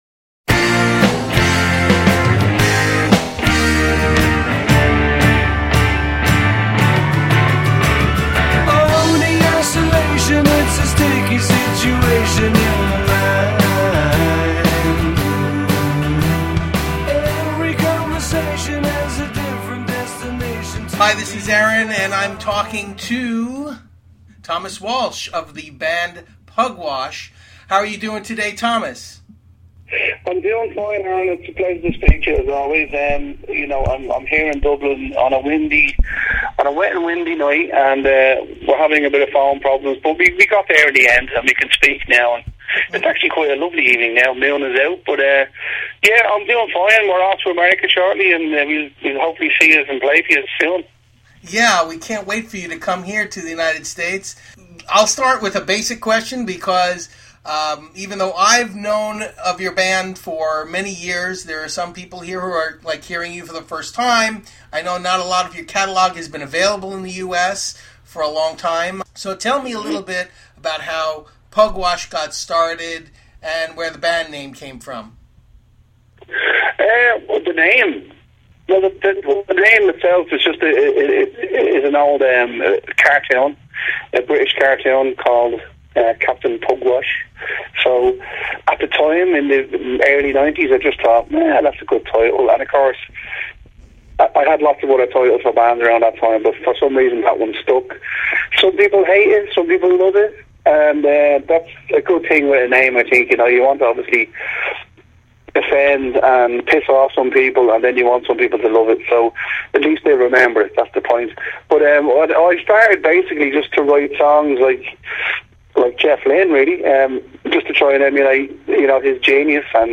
Pugwash_Interview_2.mp3